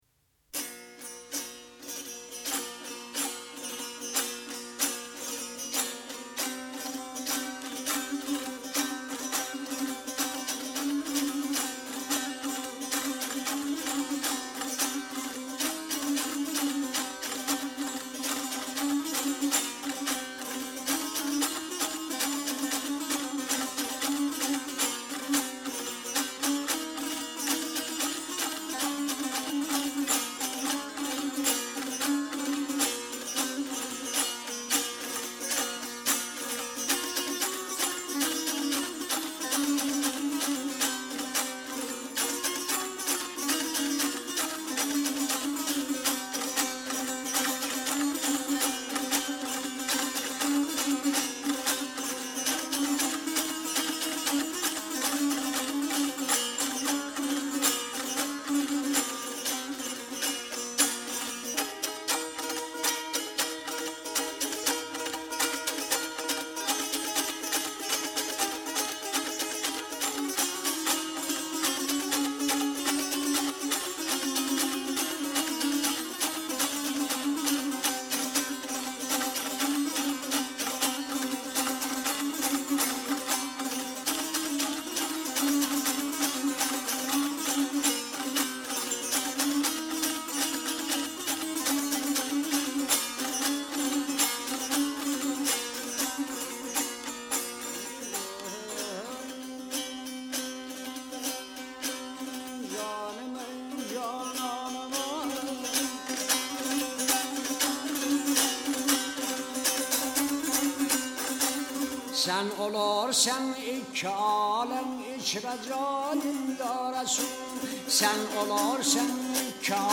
北東部ホラーサーン地方・グーチャーンのドゥタール演奏と歌